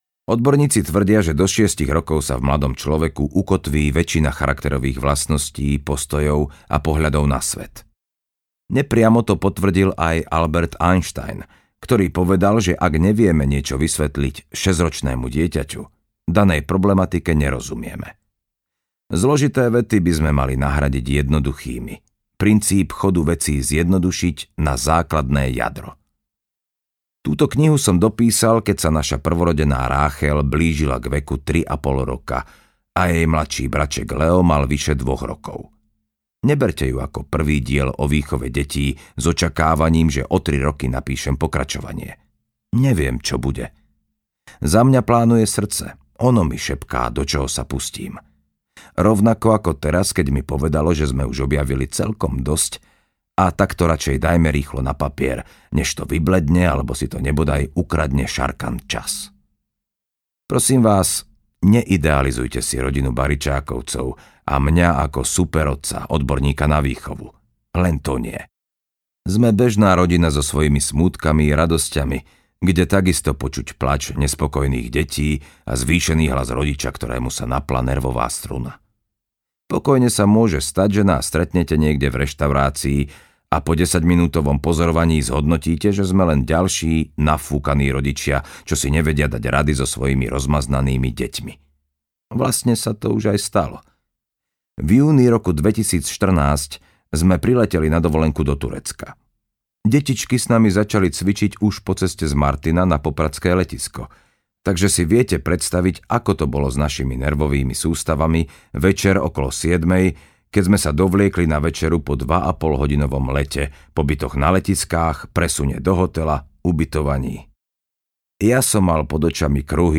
Šlabikár šťastia 3 - Dospelí deťom, deti svetu audiokniha
Ukázka z knihy
slabikar-stastia-3-dospeli-detom-deti-svetu-audiokniha